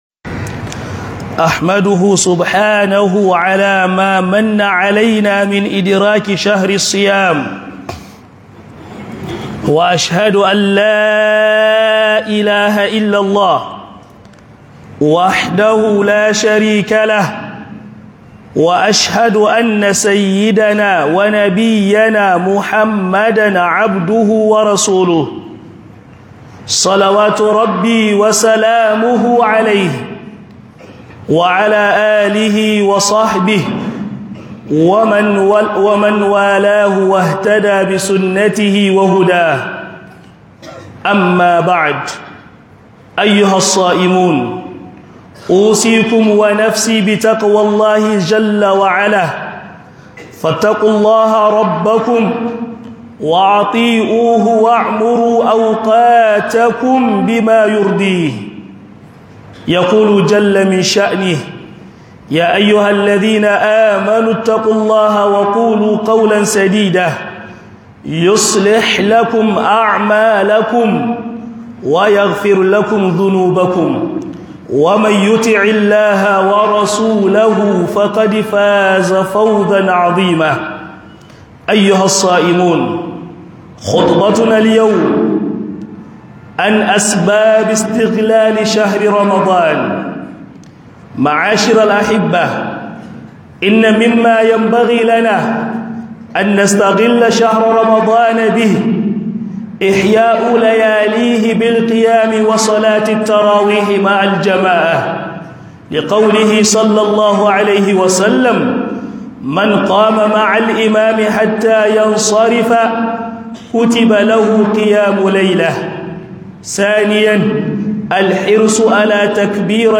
Khutubar Juma'a Mu Ribanci Watan Ramadan - HUDUBA